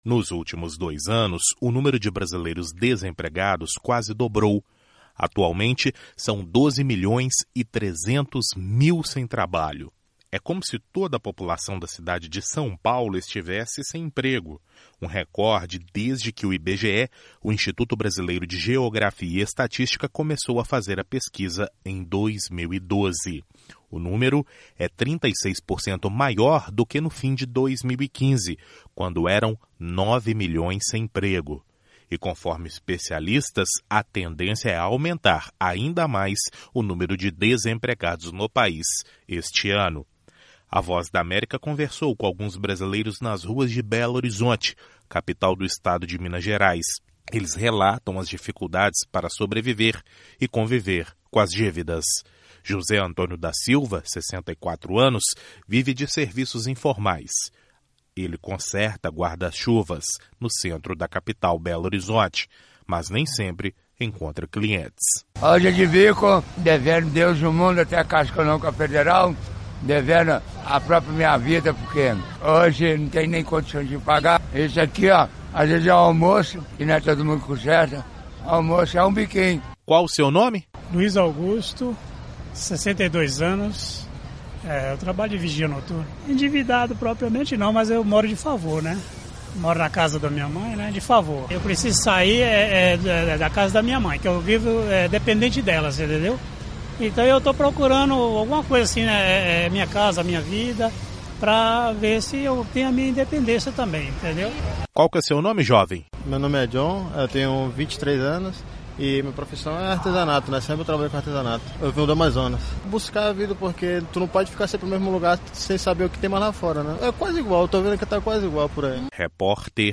A VOA conversou com alguns brasileiros nas ruas de Belo Horizonte, capital do Estado de Minas Gerais, que relatam as dificuldades para sobreviver e conviver com as dívidas.